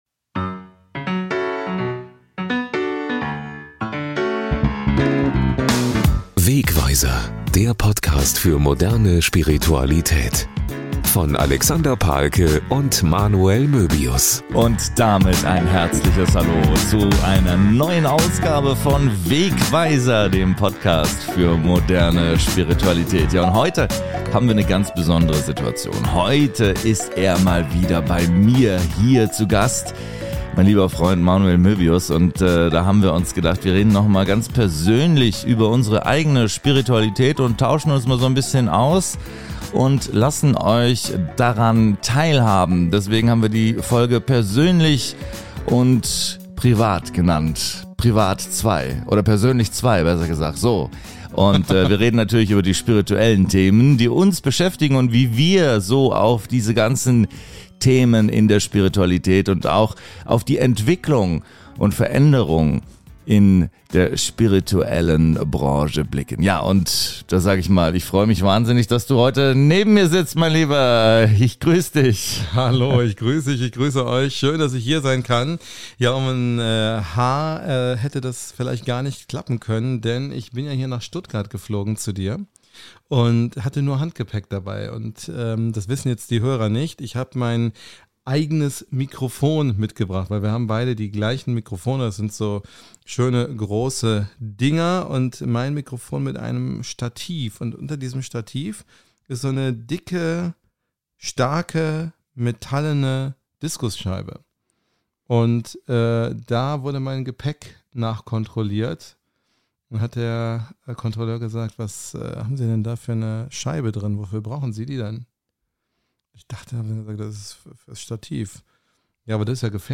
Eine spontan entstandene Plauderstunde mit so manchen Überraschungen.